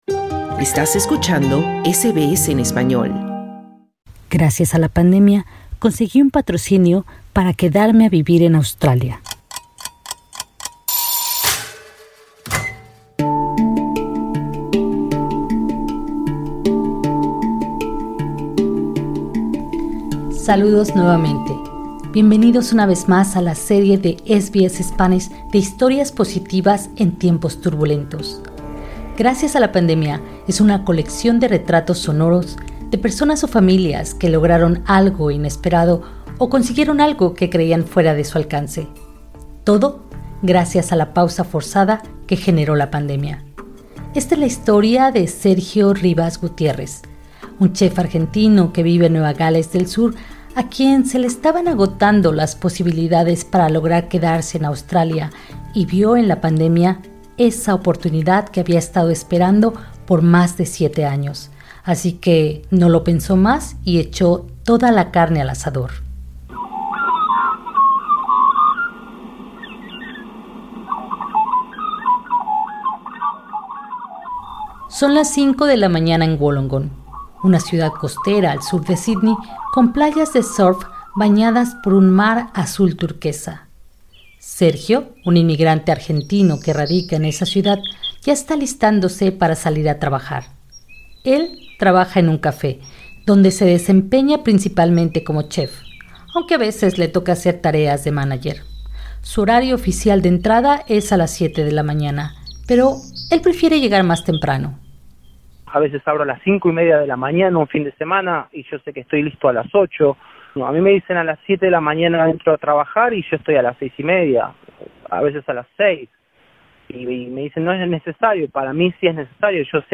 Escucha esta historia en la voz de su protagonista presionando en el ícono que se encuentra sobre esta imagen.